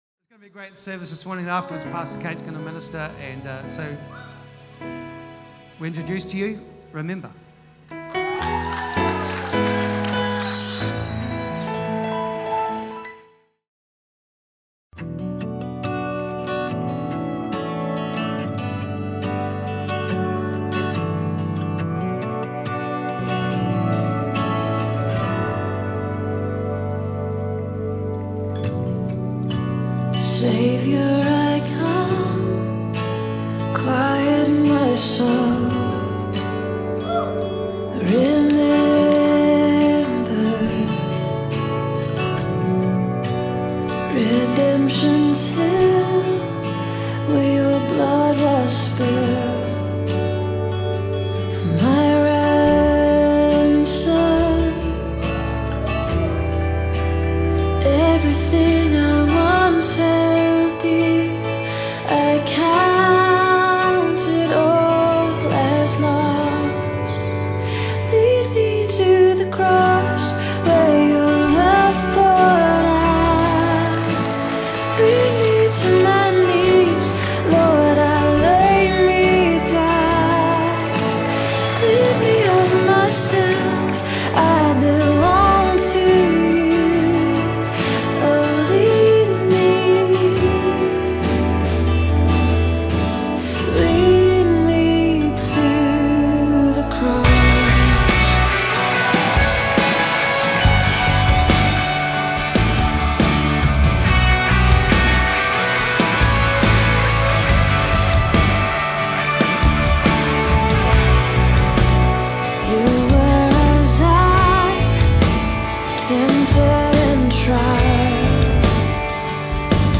Remember (Easter Service